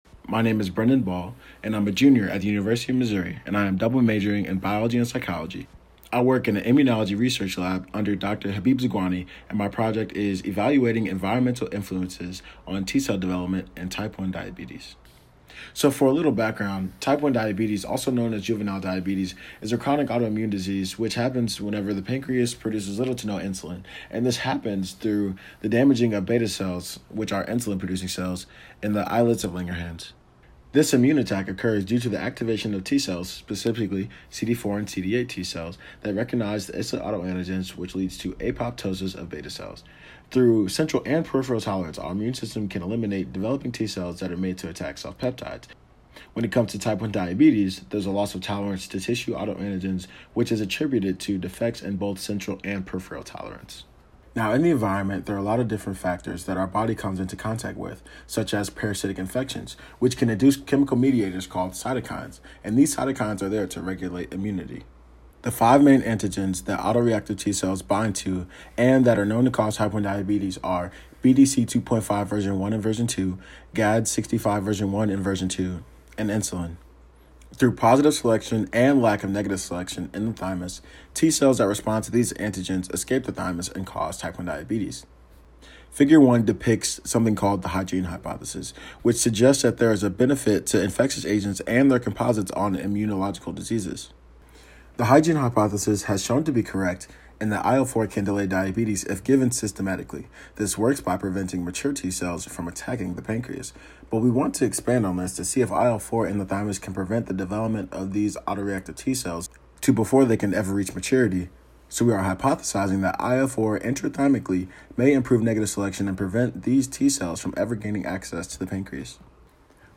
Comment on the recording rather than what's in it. Virtual presentation at the Spring 2021 University of Missouri Undergraduate Research and Creative Achievements Forum, held April 2021.